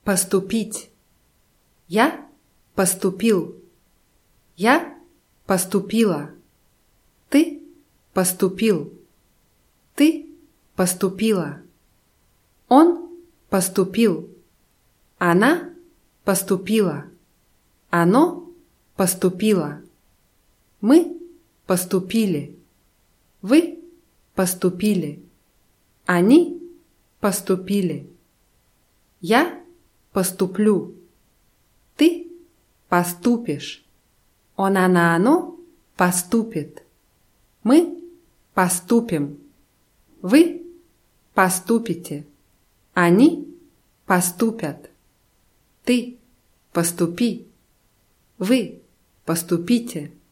поступить [paßtupʲítʲ]